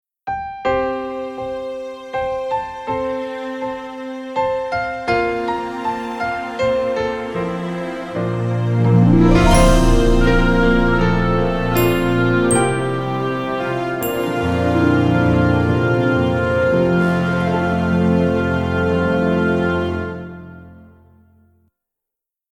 inspirational contemporary instrumental compositions